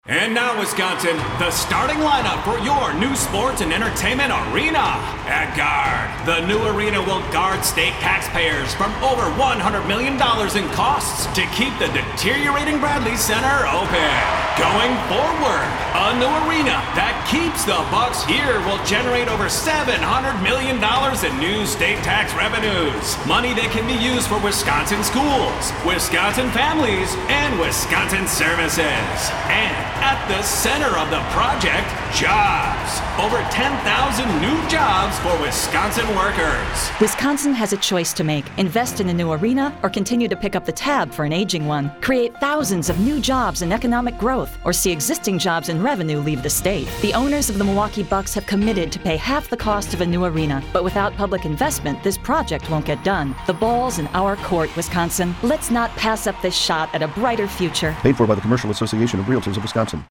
CARW will launch statewide radio spots to advocate for the proposed Bucks sports and entertainment arena beginning June 30th.
Milwaukee-Bucks-Lineup-CARW-Revised-Radio-60.mp3